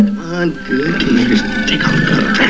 Disney's warped Humor- You know how Disney has a bunch of little sexual tidbits in their cartoons? Here's the one where Aladin says, "All good teenagers, take off your clothes."